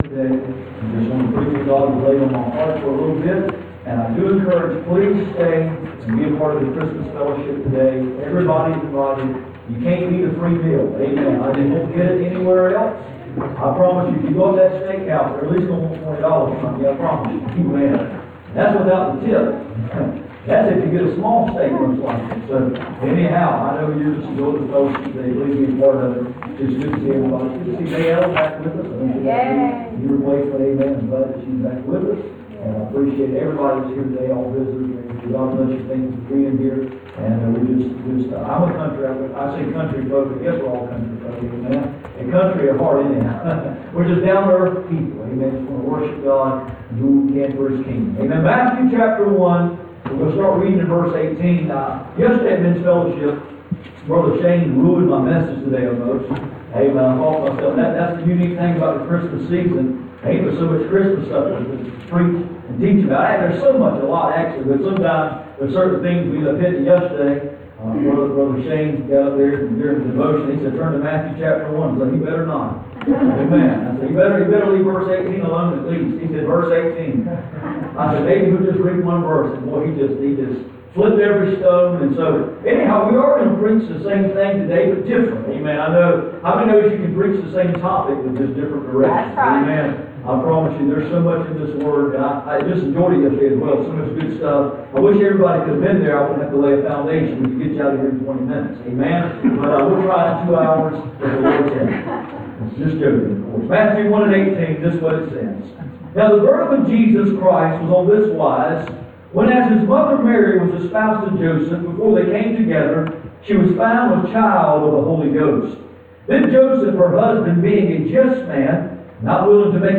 Passage: Matthew 1:18-24 Service Type: Sunday Morning